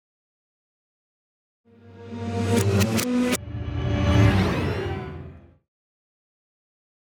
Эффект перехода в фильмах ужасов